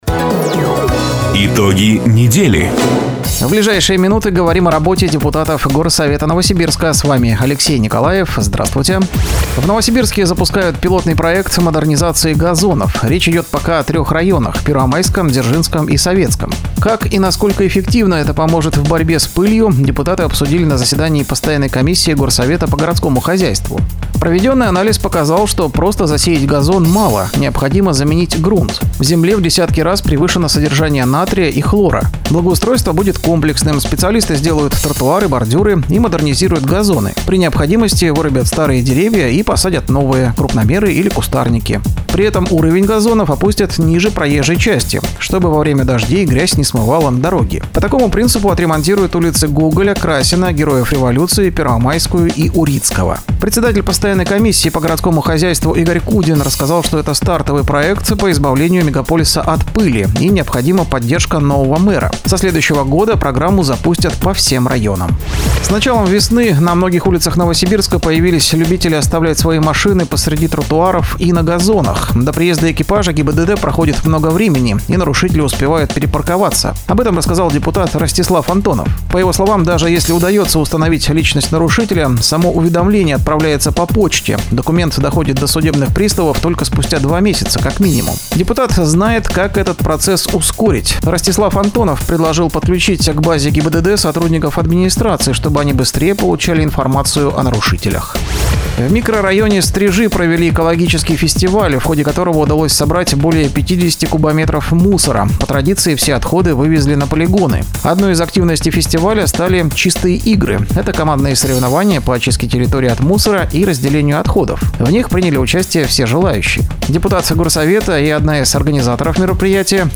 Запись программы "Итоги недели", транслированной радио "Дача" 27 апреля 2024 года.